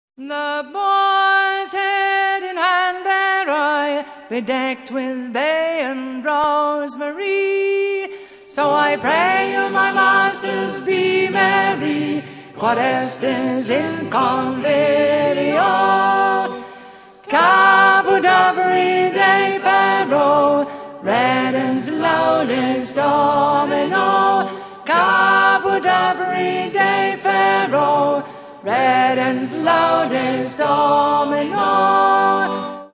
First part, 0:32 sec, mono, 22 Khz, file size: 194 Kb.